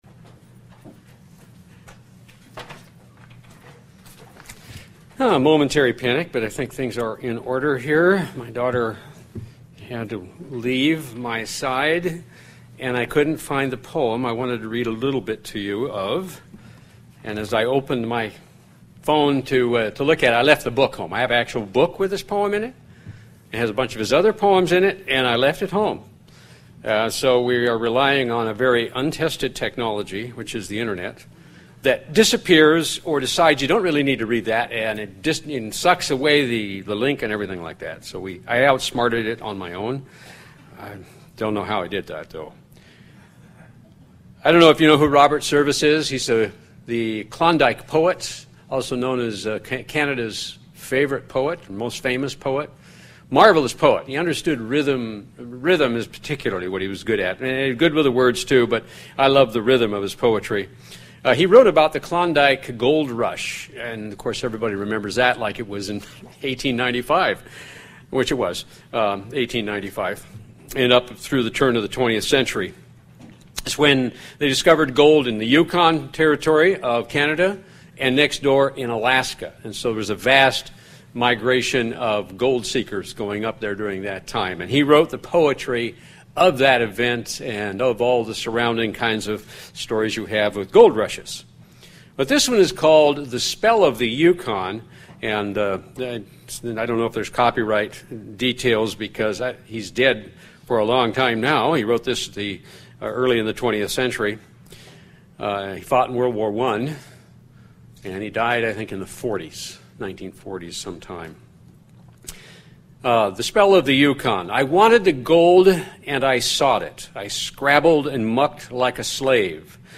Given in North Canton, OH